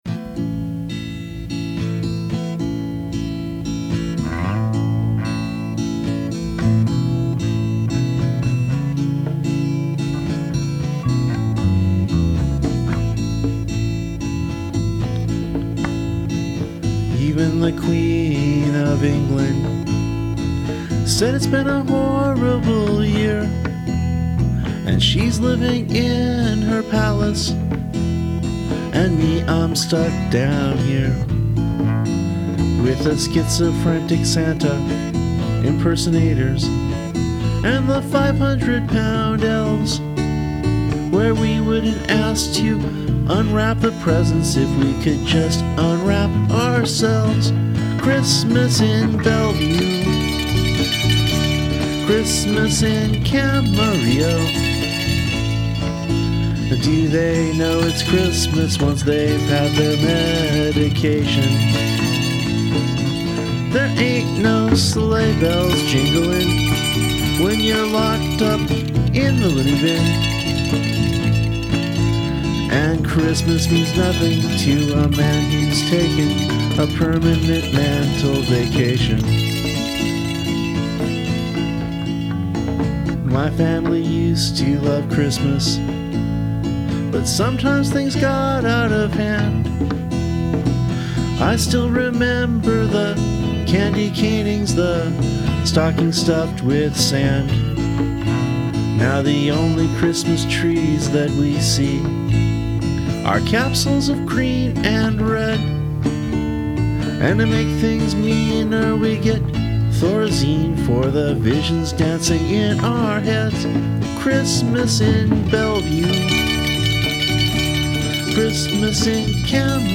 accordion and vox
guitar and vox
bass and vox
crazy windup monkey drums. Or conga, in this case.